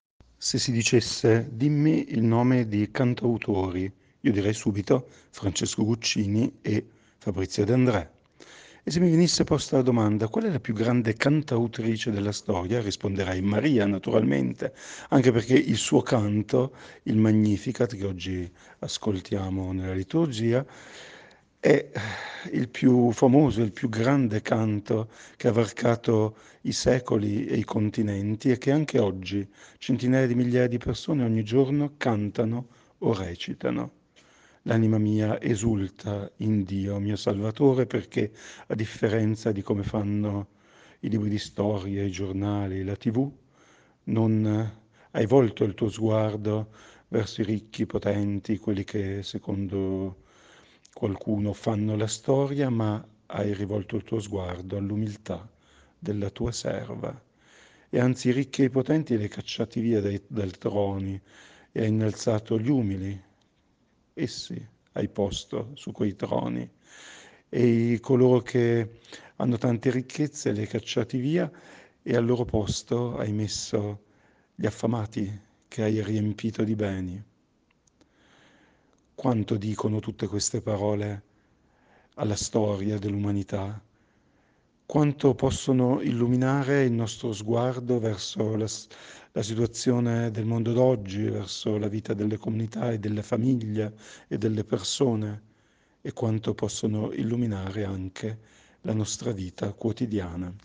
Meditazione Assunzione Beata Vergine Maria, 15 agosto 2023 – Parrocchia di San Giuseppe Rovereto